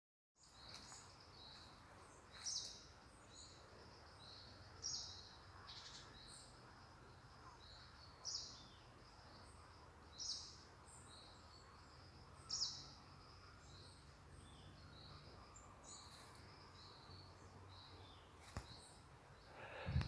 Greenish Warbler, Phylloscopus trochiloides
Administratīvā teritorijaRīga
StatusSpecies observed in breeding season in possible nesting habitat